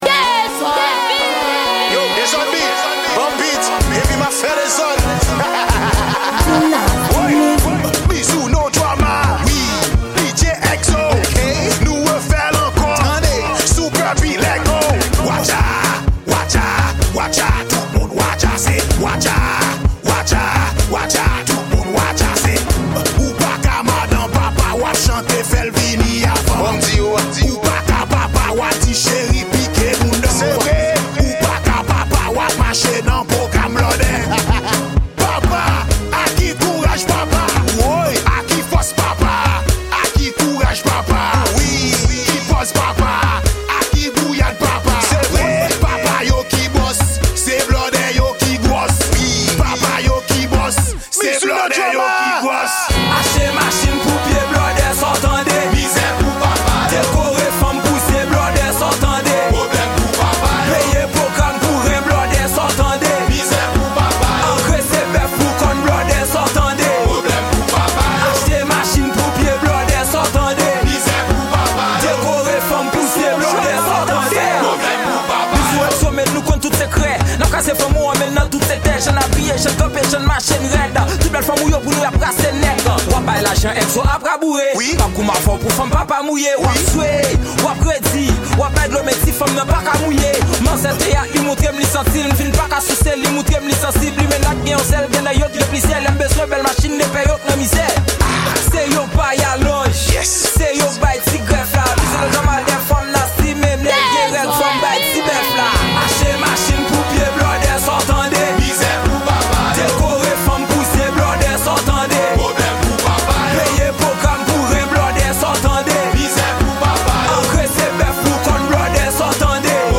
Genre: Raboday.